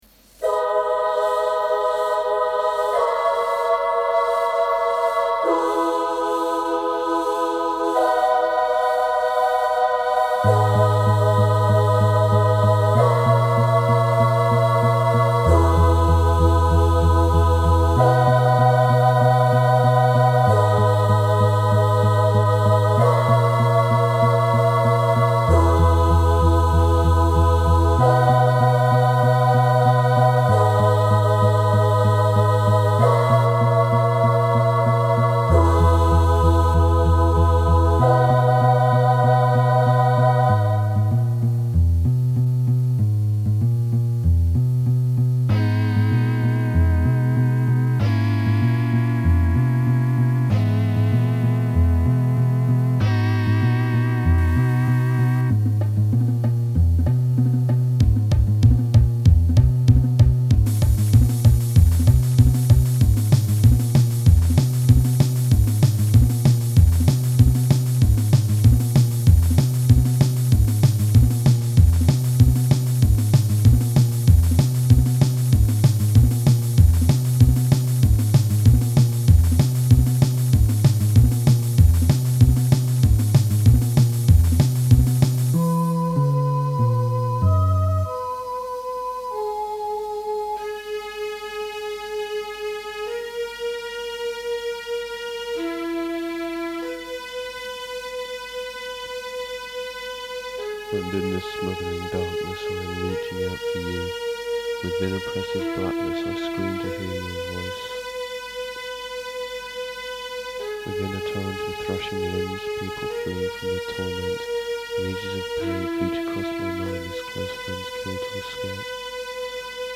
Mostly MIDI.